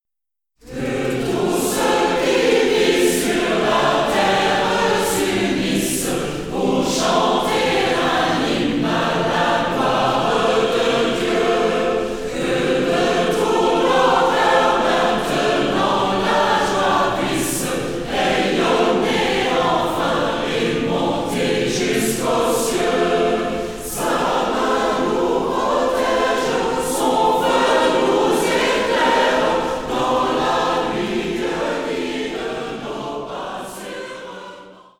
• kurzweilige Zusammenstellung verschiedener Live-Aufnahmen
Chor